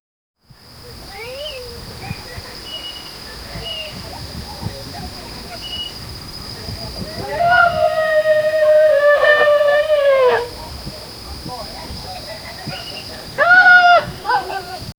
Daytime ranch ( no horses but things you hear during the daytime at a ranch
daytime-ranch--no-horses-josugywq.wav